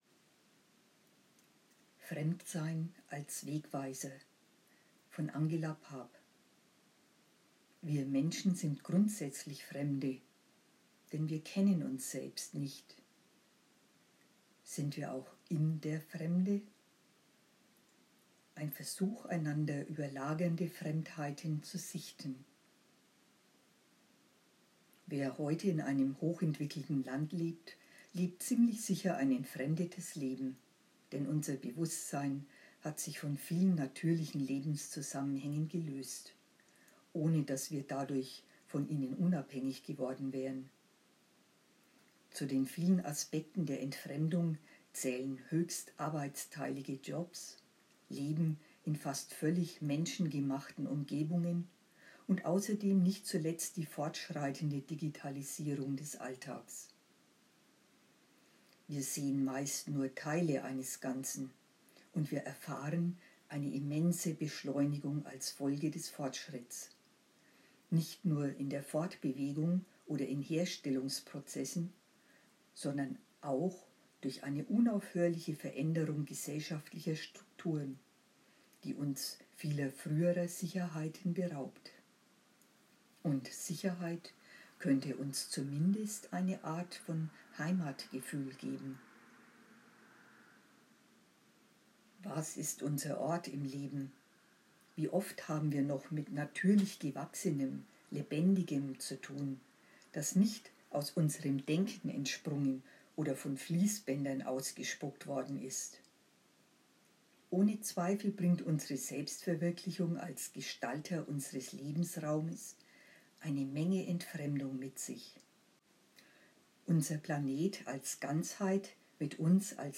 Logon-Artikel gelesen